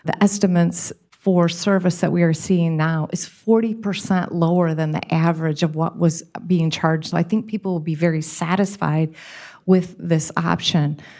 Mayor Patricia Randall says she has seen the bids, and homeowners will be pleased.